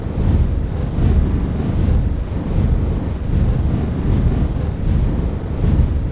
dragonflying.ogg